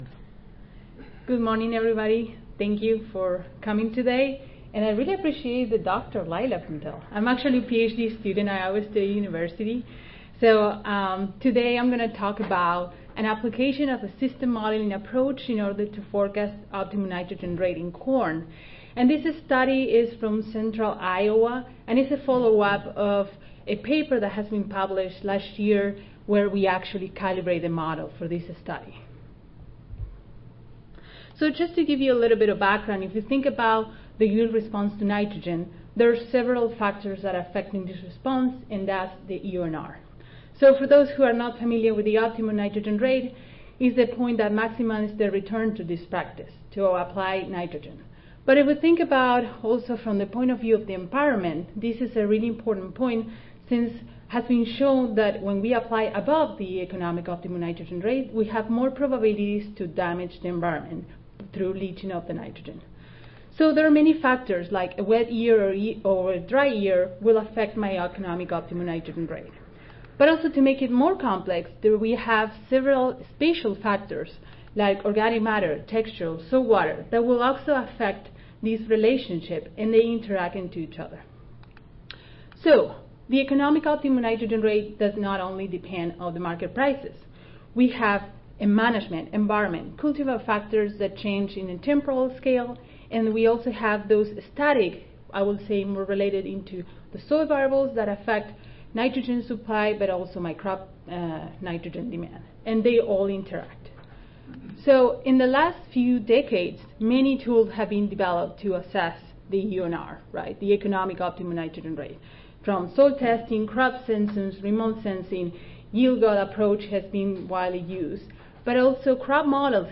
See more from this Division: ASA Section: Climatology and Modeling See more from this Session: Examples of Model Applications in Field Research Oral